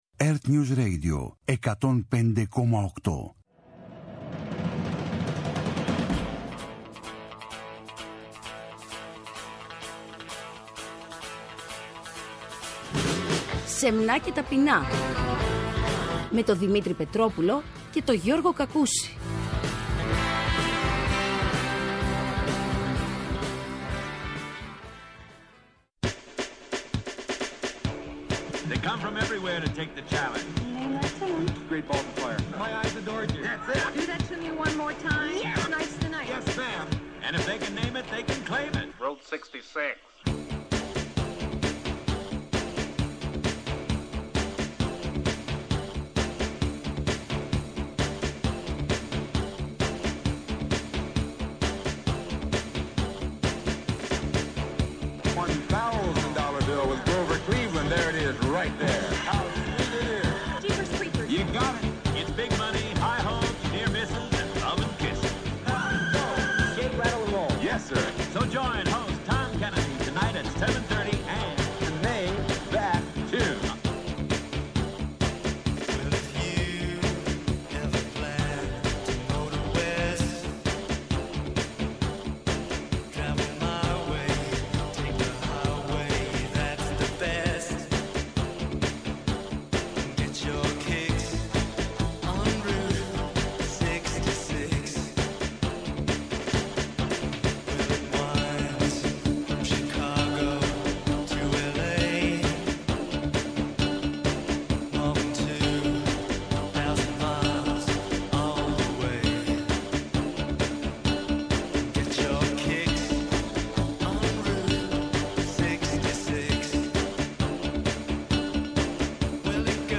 Ένα σύγχρονο ράδιο-χρονογράφημα το οποίο αποτυπώνει τον παλμό της ημέρας, τα ήθη και τις συνήθειες των ημερών μας. Η επικαιρότητα σκιτσαρισμένη με τα κανονικά της χρώματα και σκωπτική διάθεση.